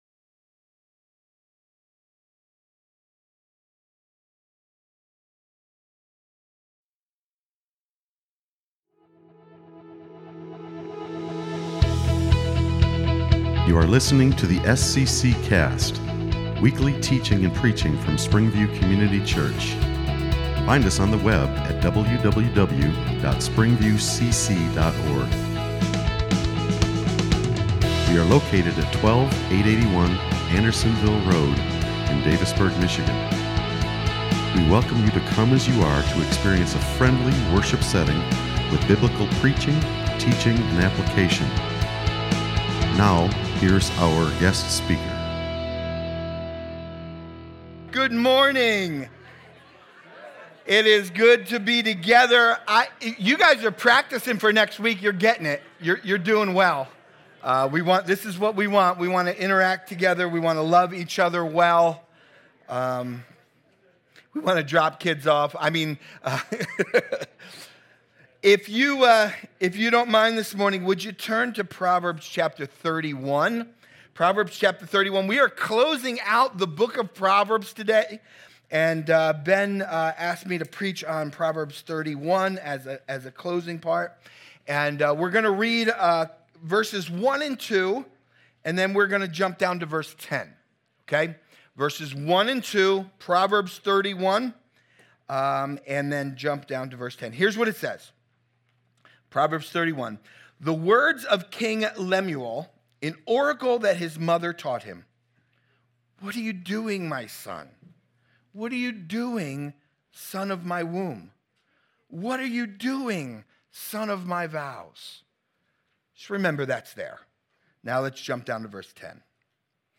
Sermons | SPRINGVIEW COMMUNITY CHURCH